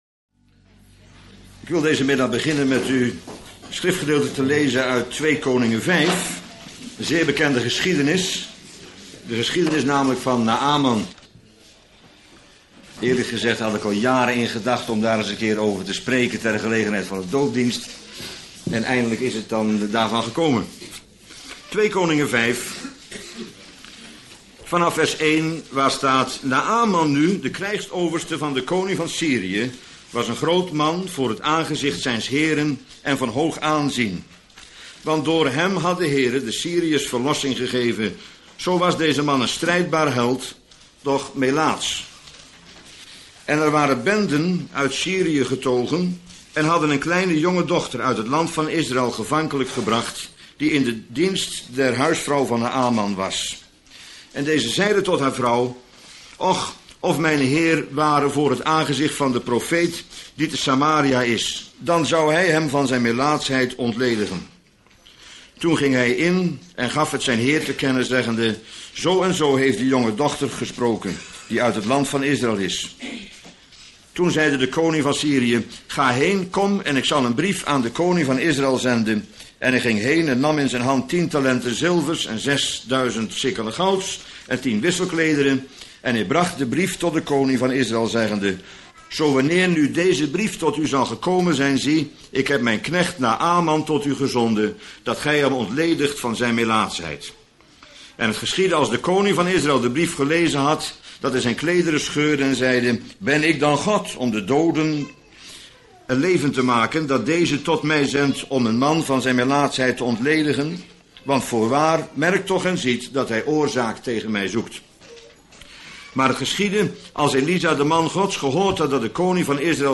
Naäman (doopdienst, 2Kon.5) - Bijbels Panorama
Bijbelstudie lezing